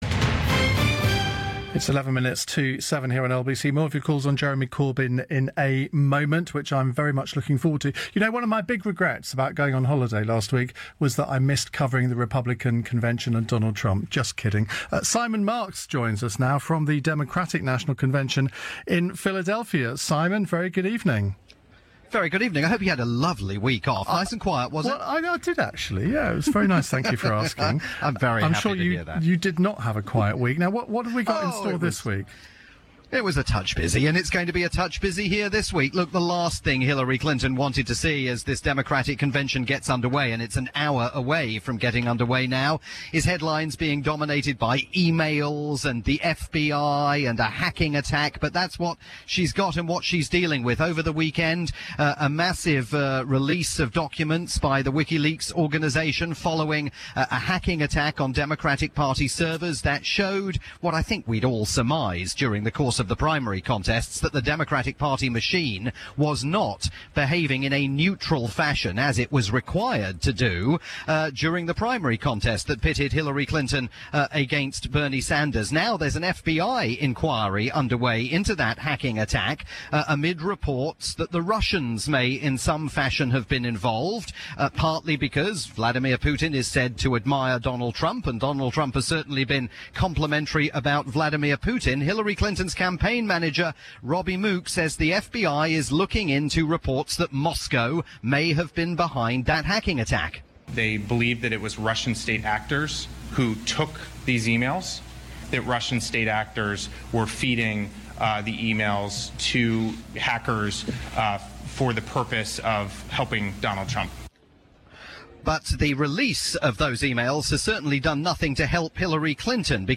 report from the Democratic Convention for LBC's Iain Dale at Drive.